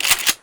Sawed-Off ~ Pump
sawedoff_pump_old.wav